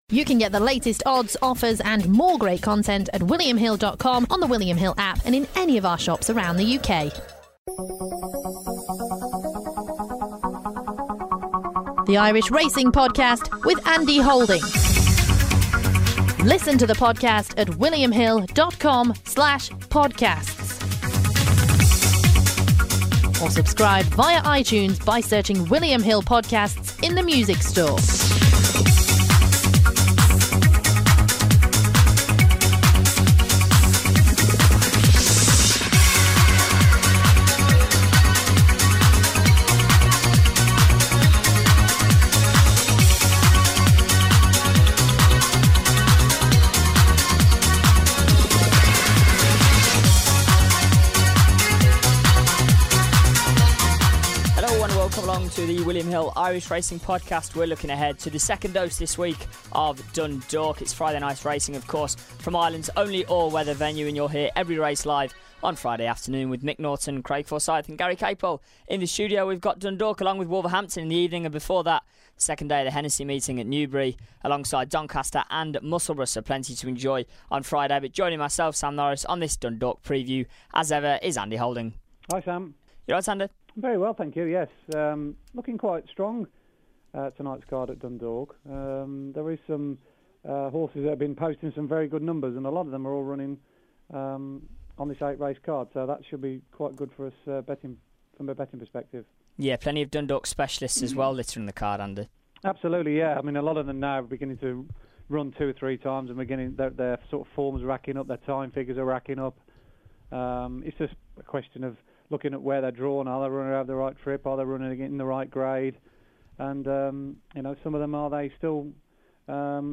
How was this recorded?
on the line to preview each race in detail before providing his selections